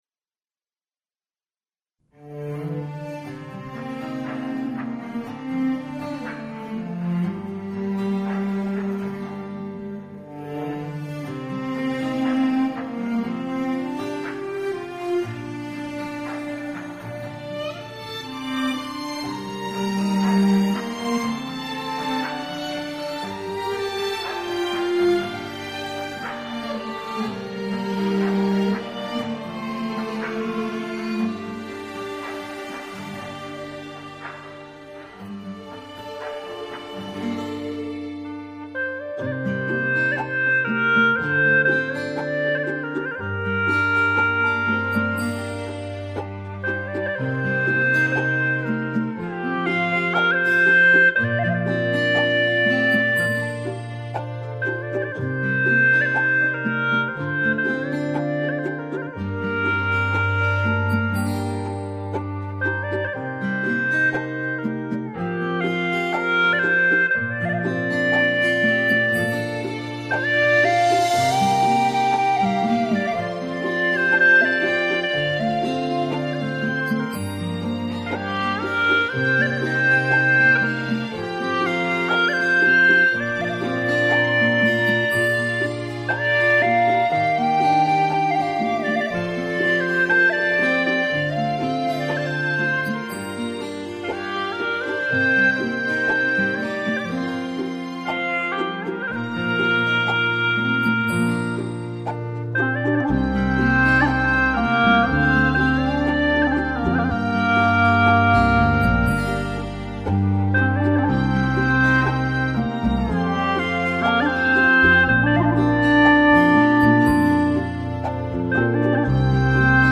调式 : 降B转C 曲类 : 民族